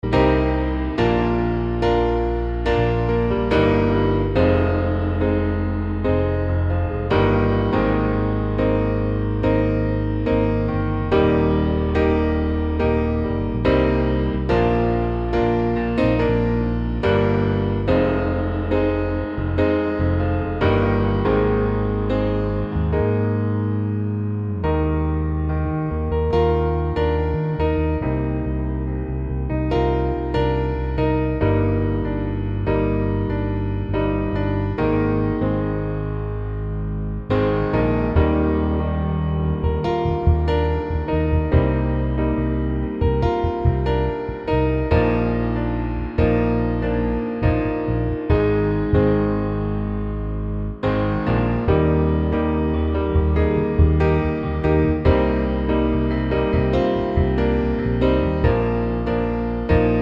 Two Semitones Down Pop (2020s) 3:44 Buy £1.50